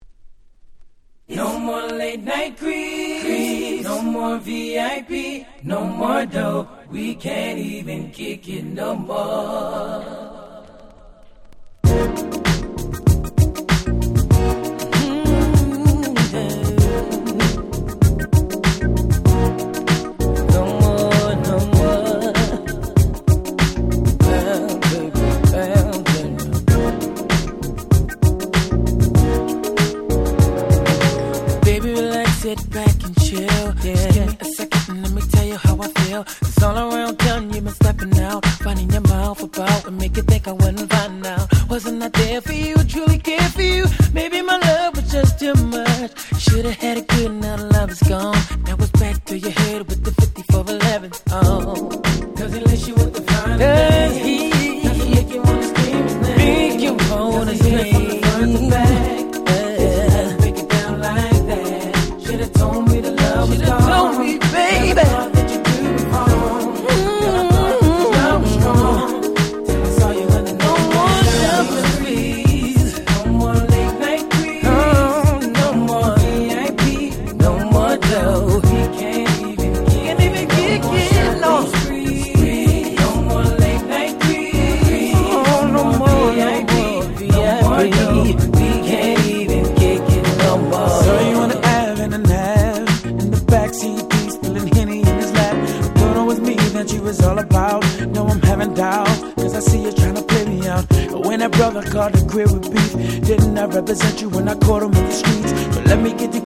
00' Super Hit R&B !!